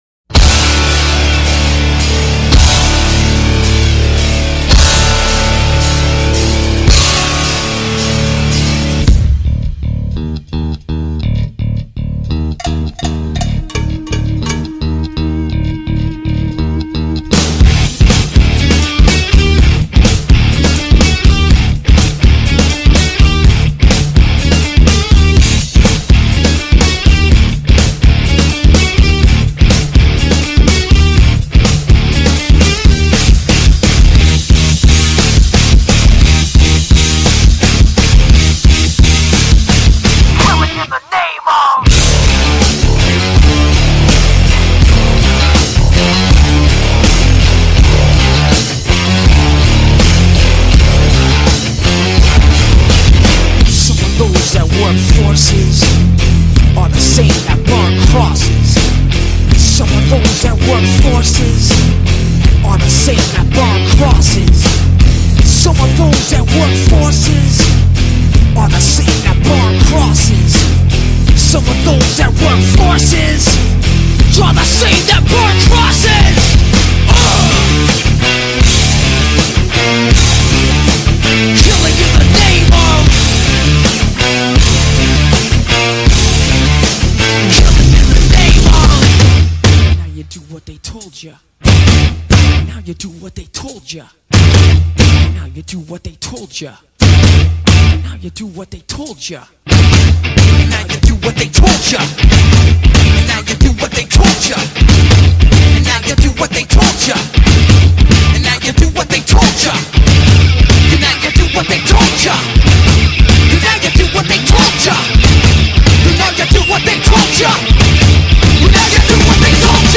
(BASS BOOSTED)